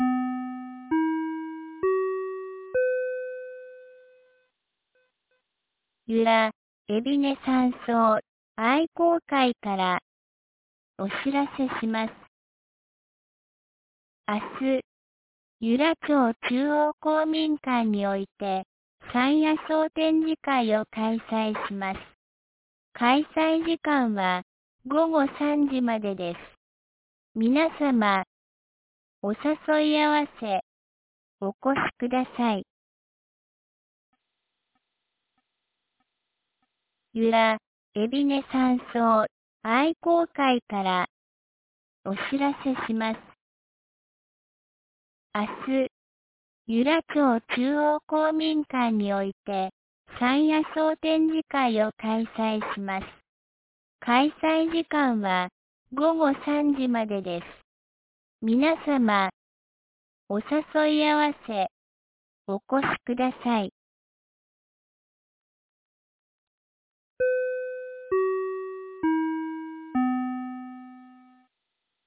2025年11月15日 17時11分に、由良町から全地区へ放送がありました。